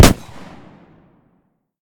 pistol-shot-01.ogg